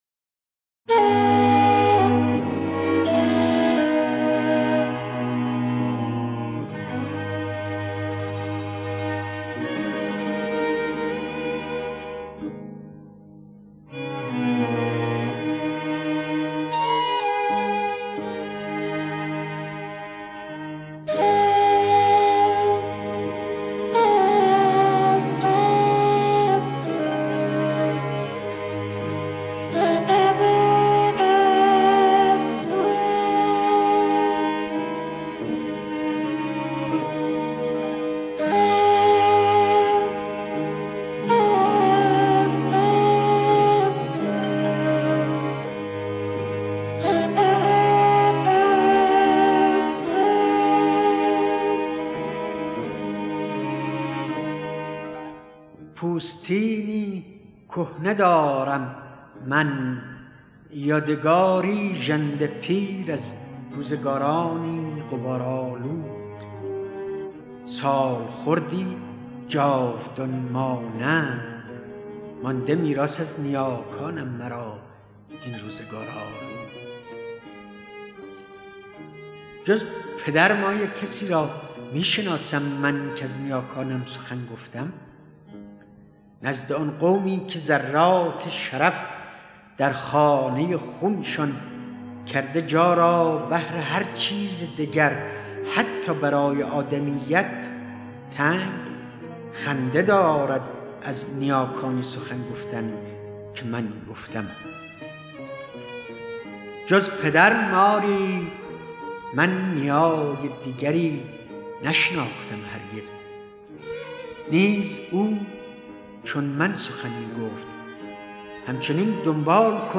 دانلود دکلمه میراث با صدای مهدی اخوان ثالث
گوینده :   [مهدي اخـوان ثالث]
آهنگساز :   مجید درخشانی